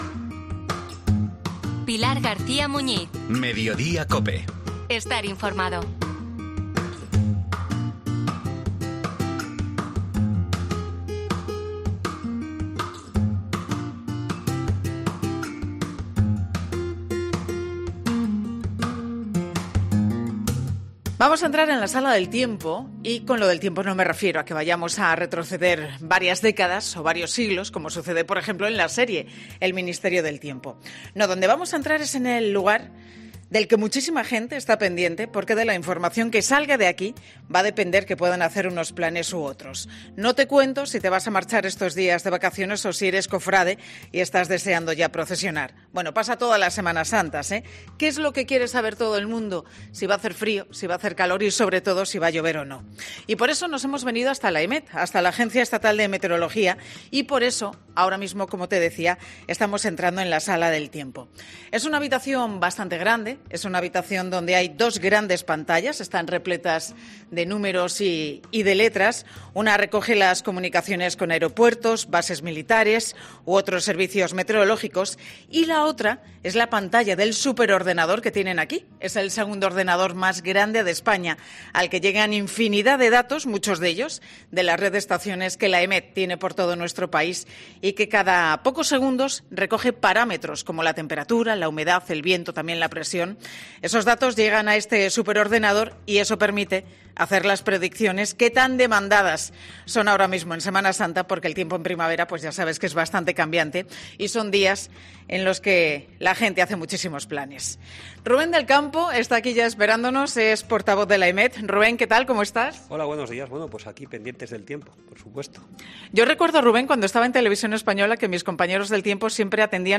La Sala del Tiempo. Quédate con ese nombre, porque es a donde Pilar García Muñiz se ha trasladado en este mediodía.